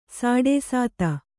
♪ sāḍē sāta